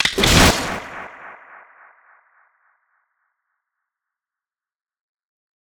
snd_groundbreak.wav